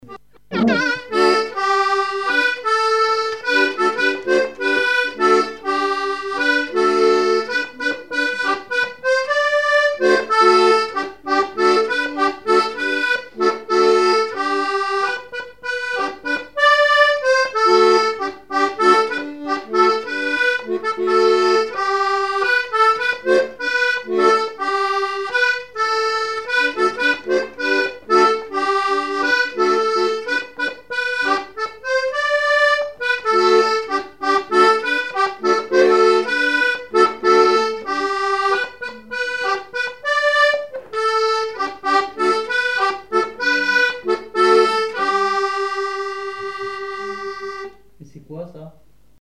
Répertoire de chansons et témoignages
Pièce musicale inédite